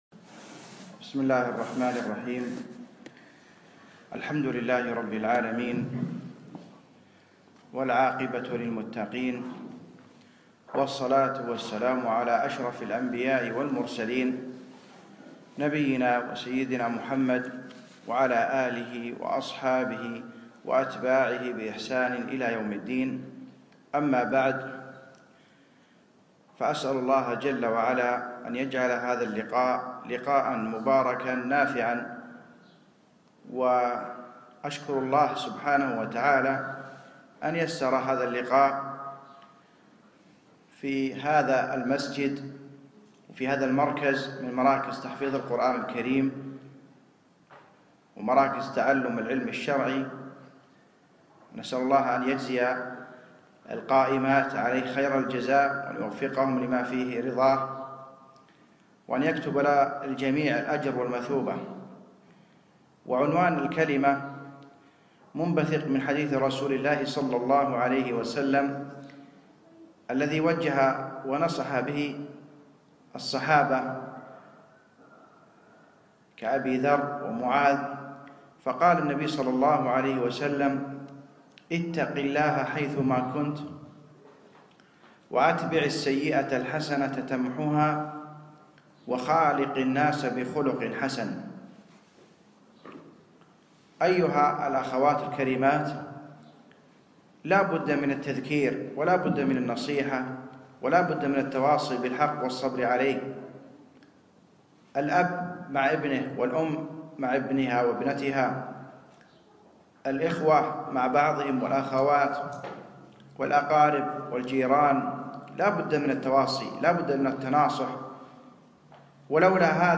يوم الخميس 25 ربيع الأخر 1437هـ الموافق 4 2 2016م في مركز دار القرآن الفردوس نسائي الفردوس